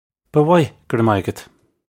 Pronunciation for how to say
Bah woy, guh ruh moy uggut. (U)
This is an approximate phonetic pronunciation of the phrase.